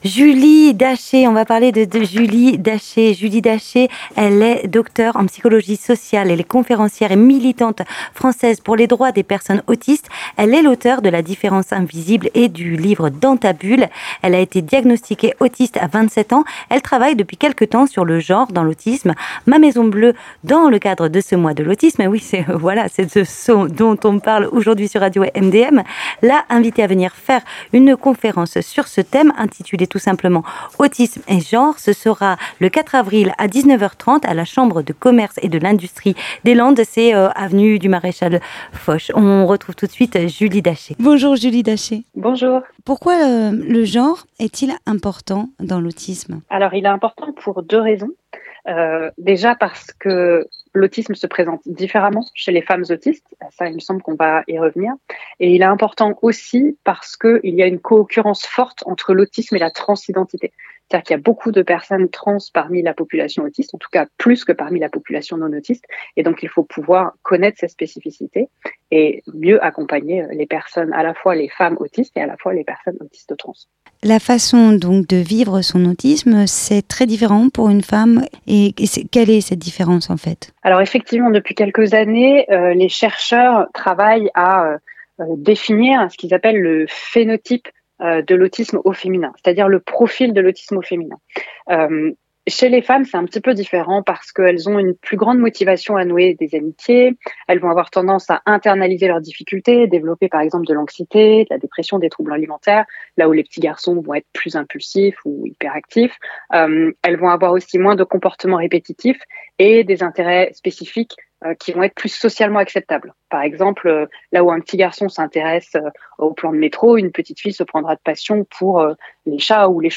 Ce sont quelques-unes des questions que nous avons évoquées dans cet interview, et qui seront longuement développées dans sa conférence de ce soir à 19 heures 30  à la CCI, entrée libre sur inscription,  vous pouvez encore vous inscrire ici –inscription conférence Autisme Et Genre